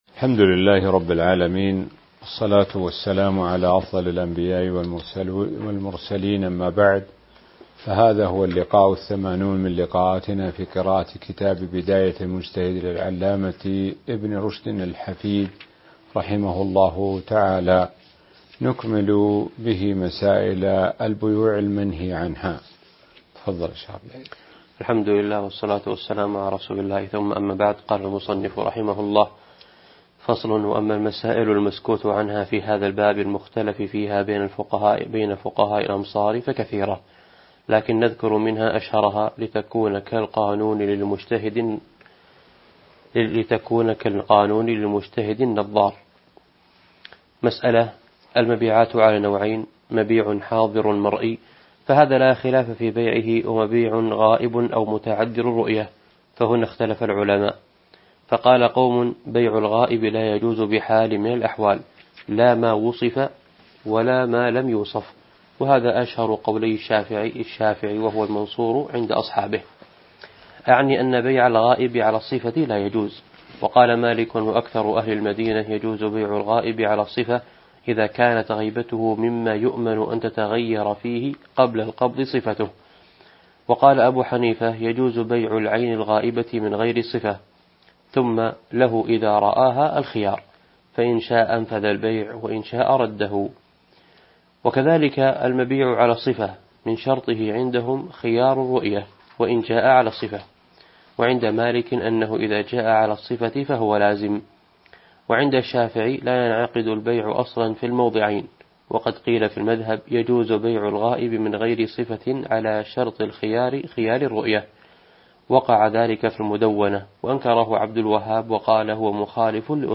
الدرس-80 [من كتاب البيوع الجزء 2 الباب 6 من البيوع المسكوت عنها إلى الباب 5 البيوع المنهي عتها من أجل الضرر ]